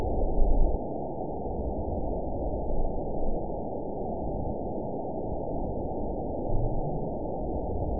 event 911621 date 03/05/22 time 12:26:41 GMT (3 years, 2 months ago) score 8.96 location TSS-AB02 detected by nrw target species NRW annotations +NRW Spectrogram: Frequency (kHz) vs. Time (s) audio not available .wav